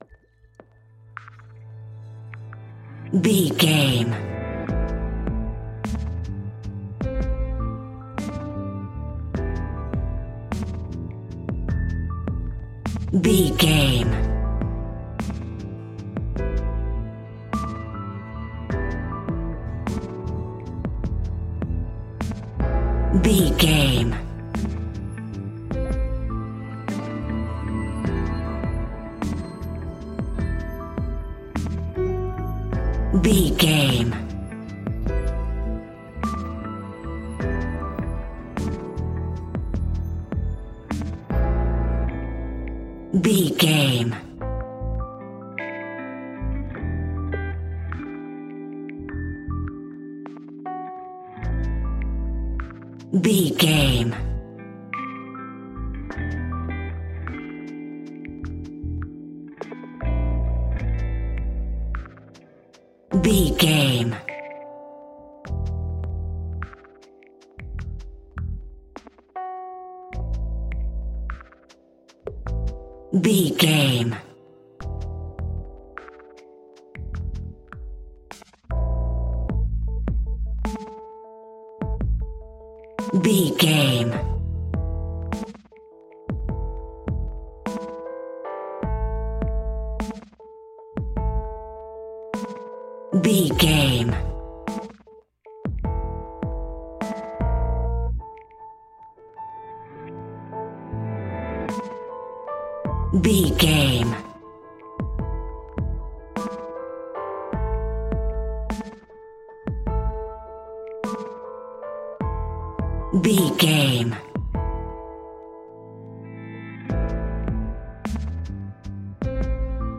Fast paced
Uplifting
Ionian/Major
A♭
hip hop
instrumentals